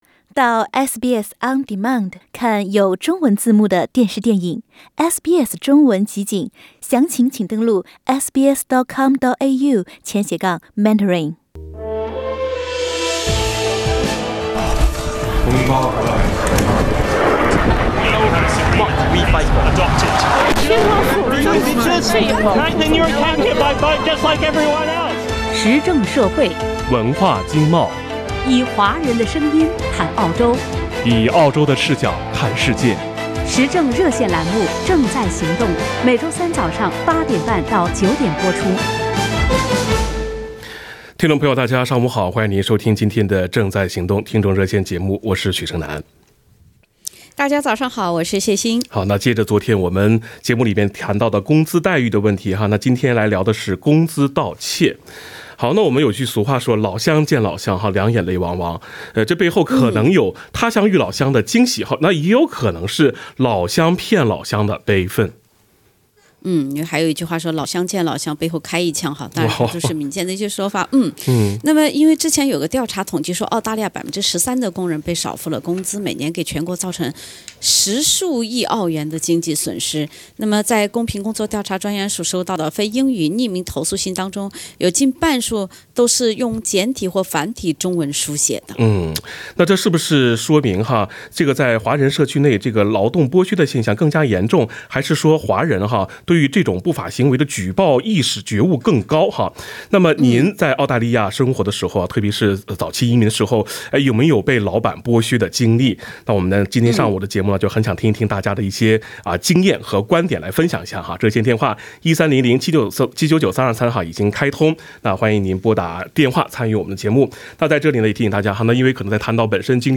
这是由于华人社区内的劳动剥削更严重还是举报意识觉悟高？（点击封面图片，收听热线节目回放）